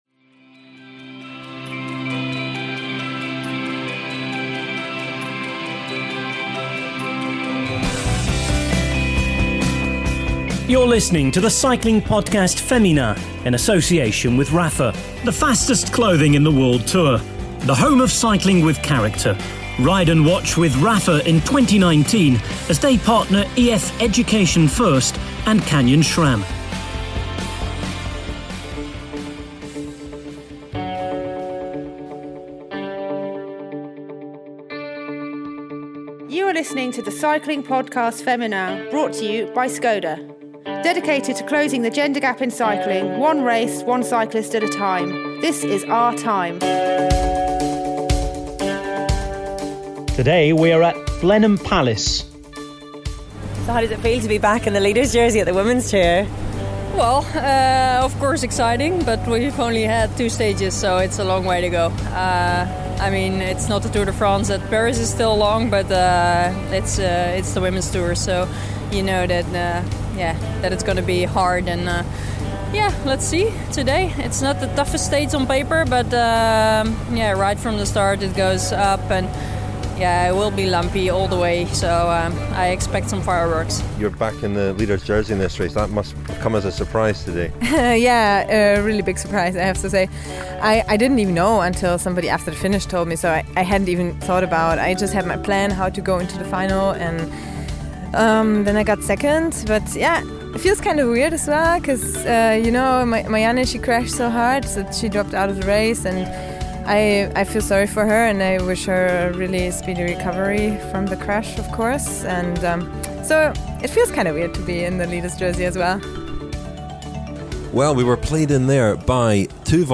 There are also interviews